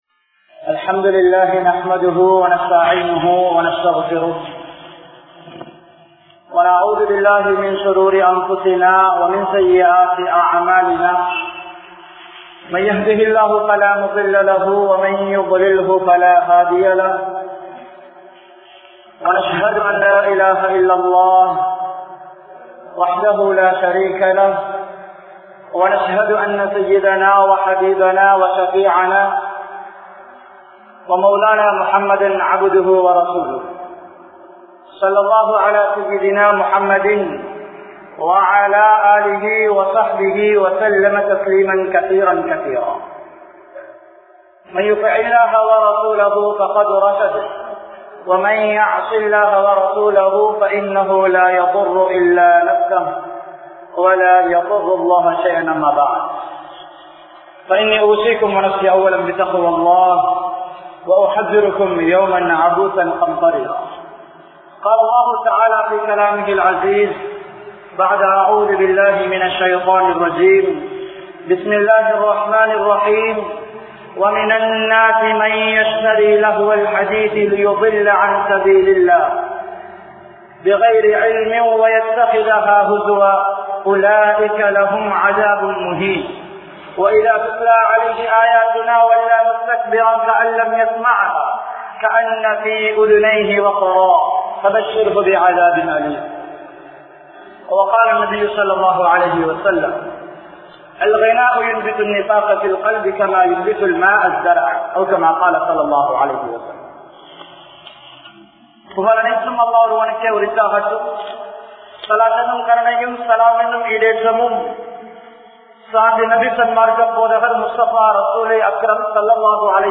Music il Inbam Kaanum Samooham(மியூசிக் இல் இன்பம் காணும் சமூகம்) | Audio Bayans | All Ceylon Muslim Youth Community | Addalaichenai
Matale, Gongawela Jumua Masjidh